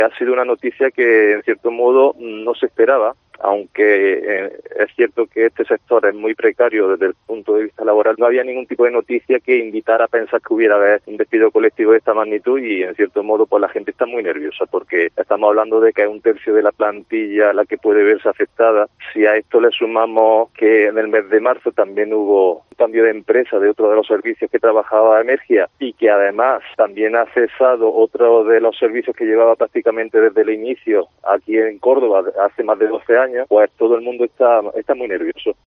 Córdoba